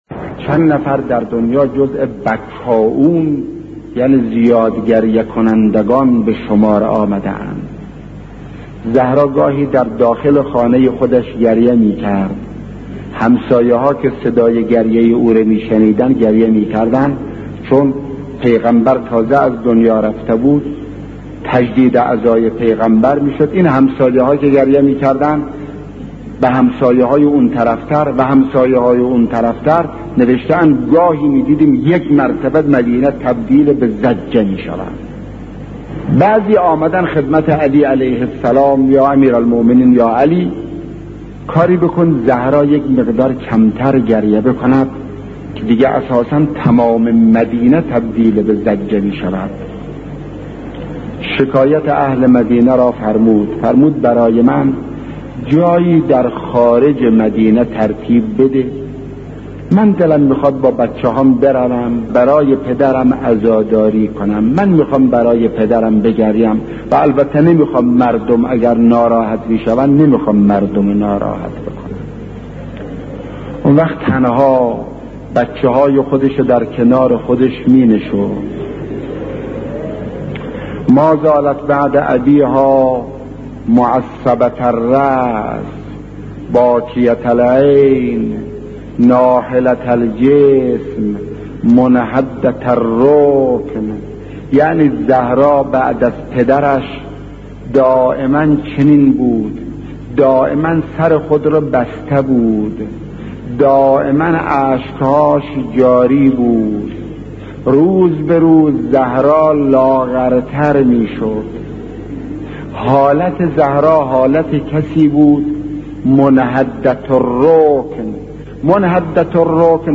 دانلود مداحی گریه های حضرت زهراس - دانلود ریمیکس و آهنگ جدید
روضه حضرت زهرا(س) توسط استاد مطهری (2:42)